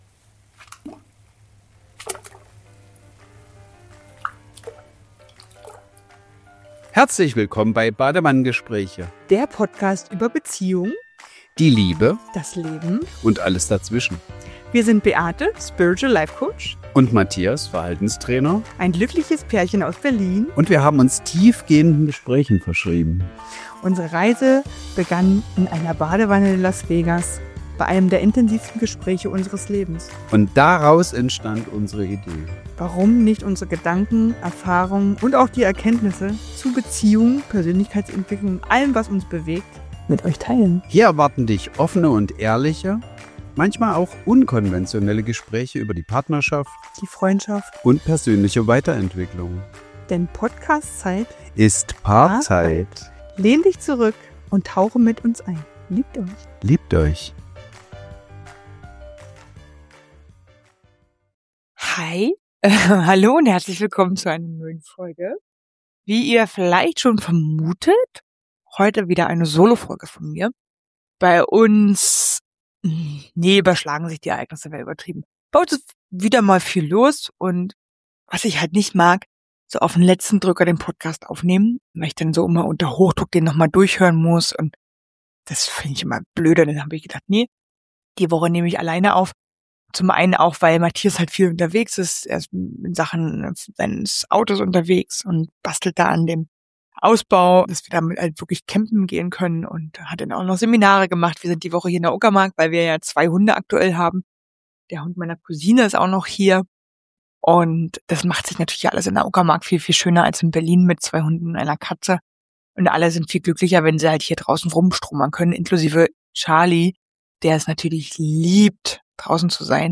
In dieser Solo-Folge von Badewannengespräche spreche ich über die Energie des Mondes, über Neumond und Vollmond und darüber, warum viele Menschen spüren, dass der Mond etwas in uns bewegt. Ich nehme dich mit in die Welt der Mondzyklen und erzähle, warum wir als Menschen ebenfalls in Zyklen leben – mit Phasen von Wachstum, Klarheit, Rückzug und Transformation.